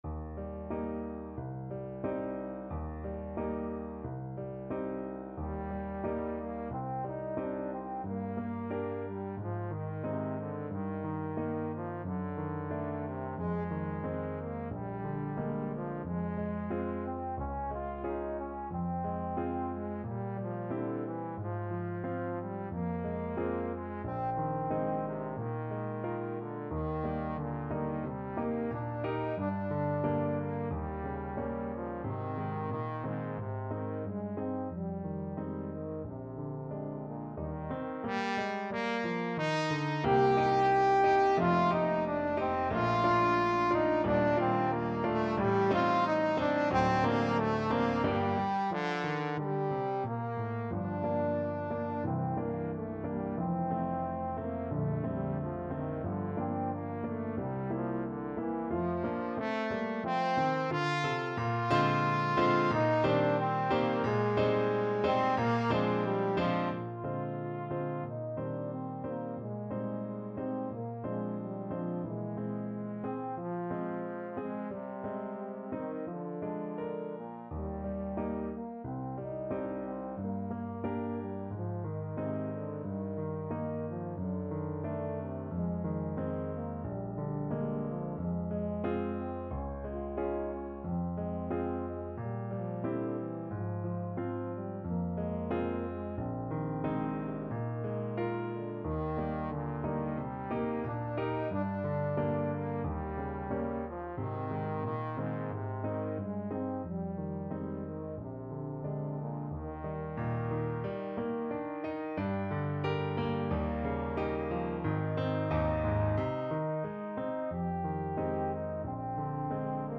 Trombone version
2/4 (View more 2/4 Music)
~ = 100 Allegretto con moto =90
Classical (View more Classical Trombone Music)